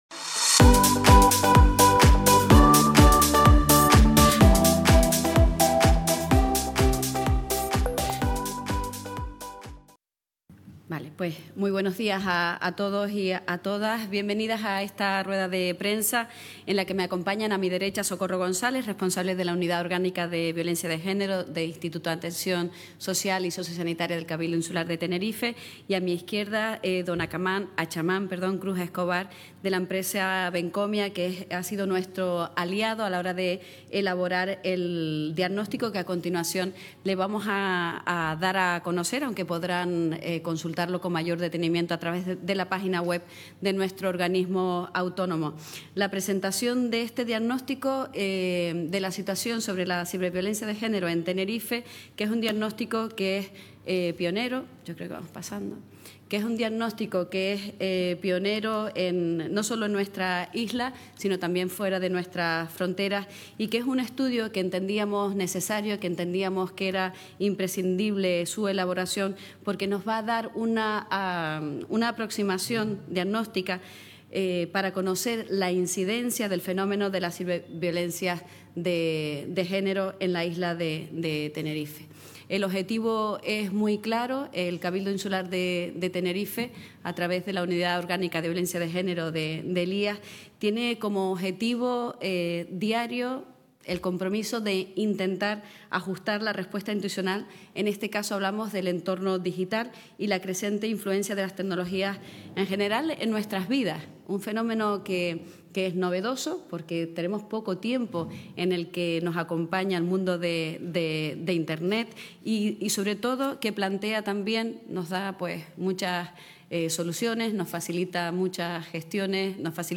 El Cabildo de Tenerife ha elaborado un diagnóstico sobre la situación de las ciberviolencias de género en la isla, tal y como ha explicado hoy en rueda de prensa la consejera de Acción Social de la institución insular, Águeda Fumero.